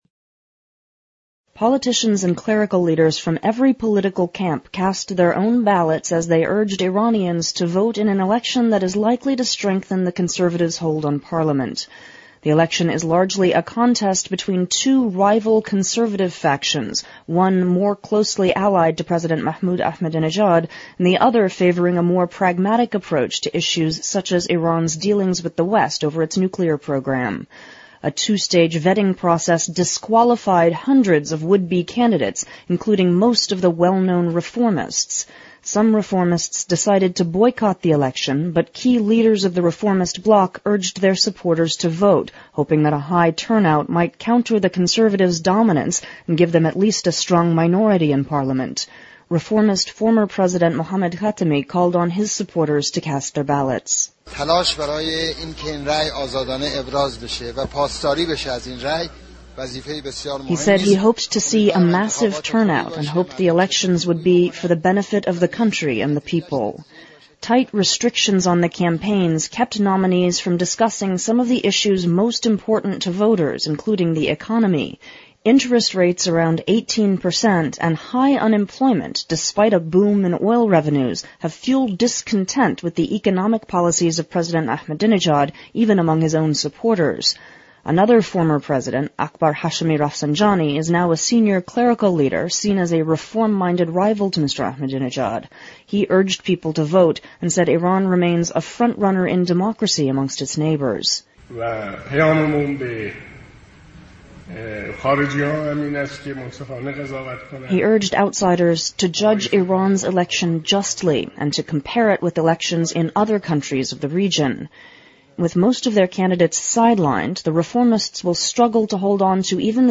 VOA常速英语2008年-Iranians Vote for New Parliament 听力文件下载—在线英语听力室